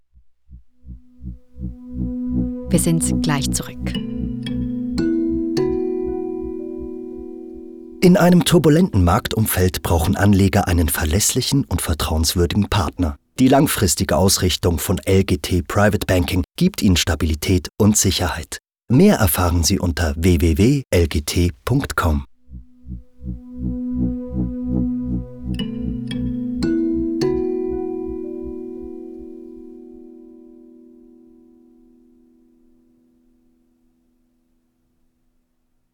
mid-roll.
LGT_Midroll_N.mp3